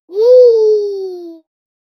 Joyful non-verbal exclamation from a small child in an AR game after winning. High-pitched, happy, playful, and natural – like a cheerful giggle, squeal, or excited 'woo!' No words, just expressive emotion suitable for augmented reality interactions. 0:02
joyful-non-verbal-exclama-cxt66opm.wav